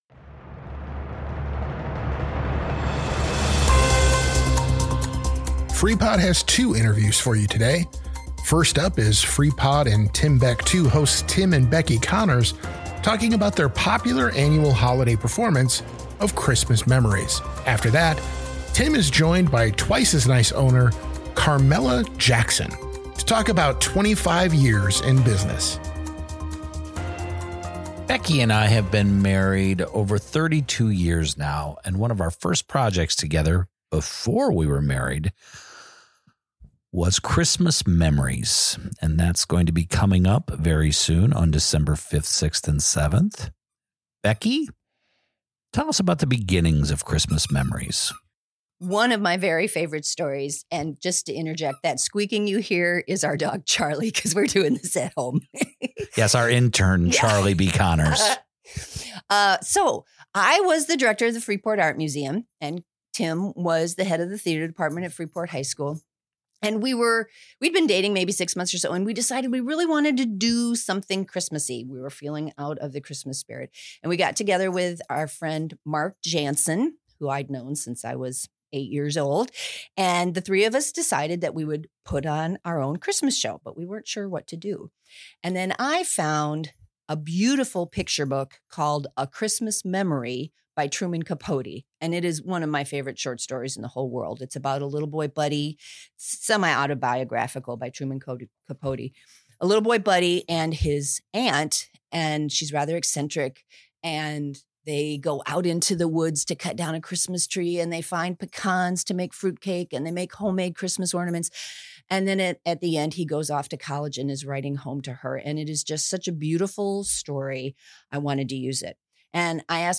Freepod - Freepod Interview Double Header! Christmas Memories and Twice As Nice